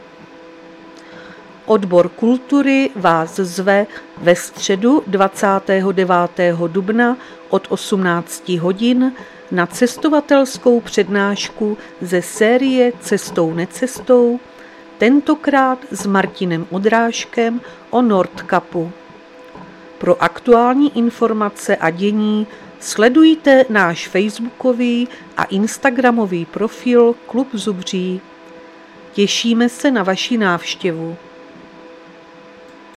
Záznam hlášení místního rozhlasu 13.4.2026